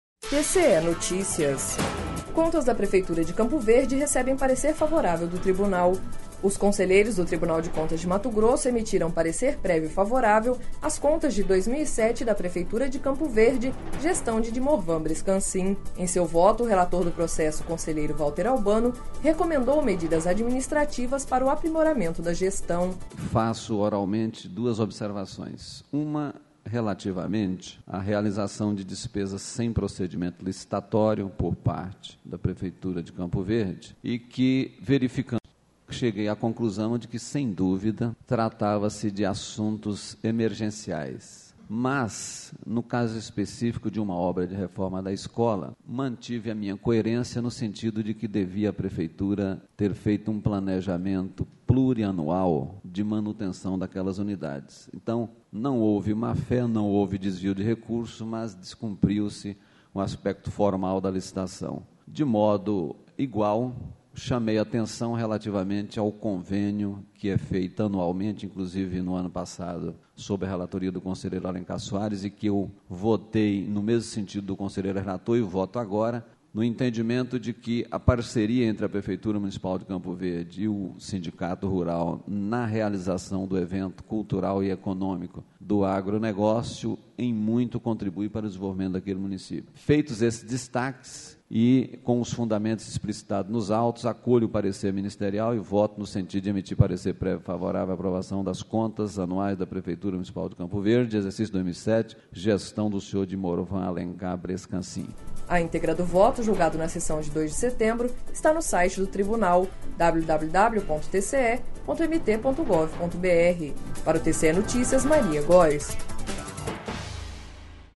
Sonora: Valter Albano – conselheiro do TCE